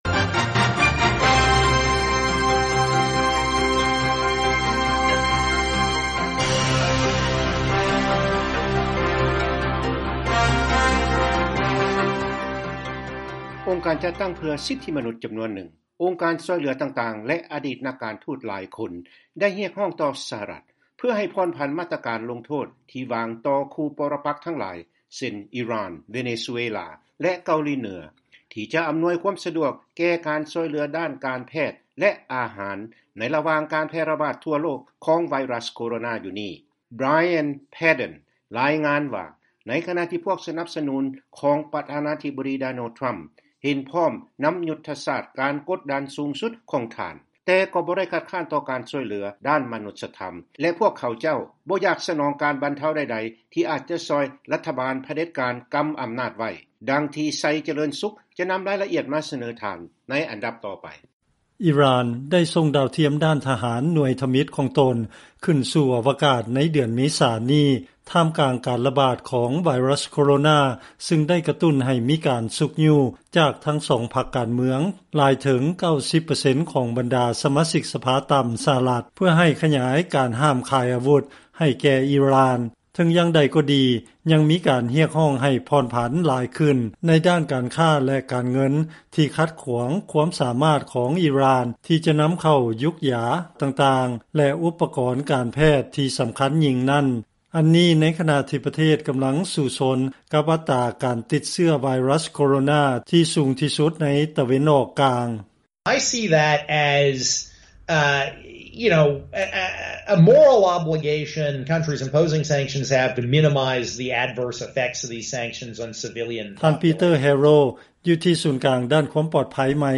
ເຊີນຟັງລາຍງານ ໂຣກລະບາດ COVID-19 ເຮັດໃຫ້ມາດຕະການລົງໂທດ ກົດດັນແລະສ້າງຄວາມເຈັບແສບ ຫຼາຍຂຶ້ນ ຕໍ່ຄູ່ປໍລະປັກ ຂອງ ສຫລ